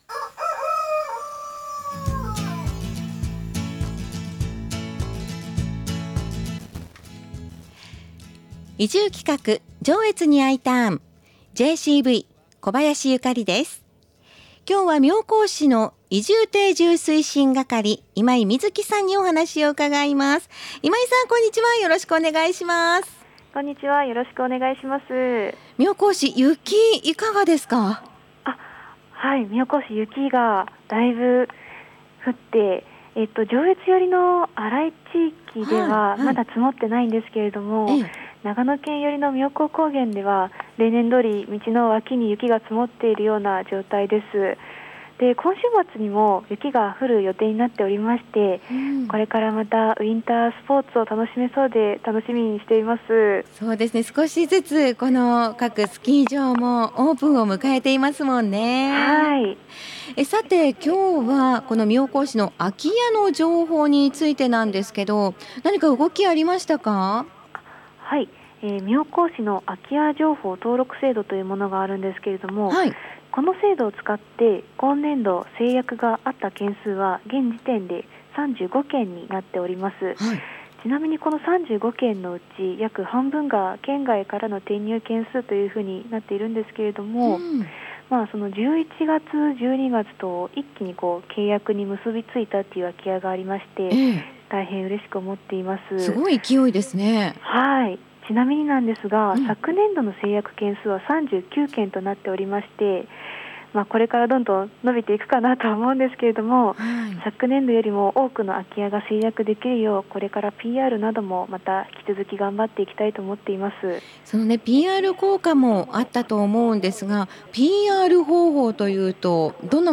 電話出演してくださいました('ω')ノ